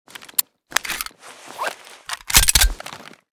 de_reload_empty.ogg